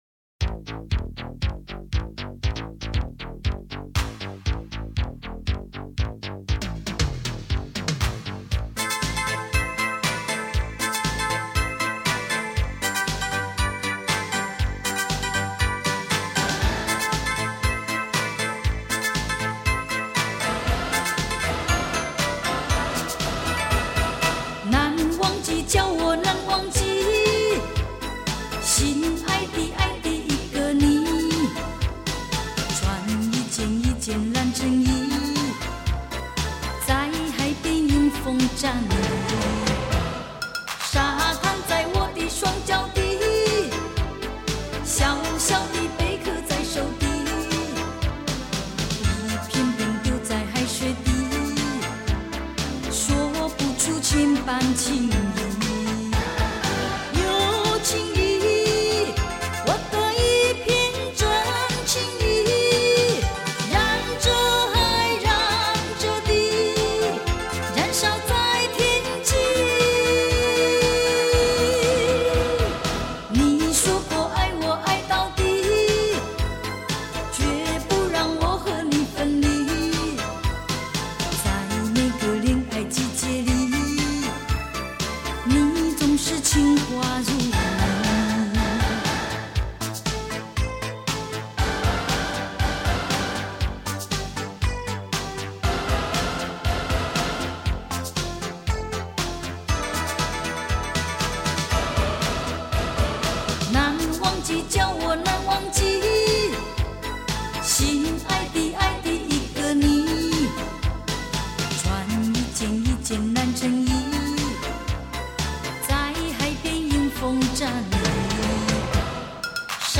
情歌篇篇②
录音室：Oscar Studio S'pore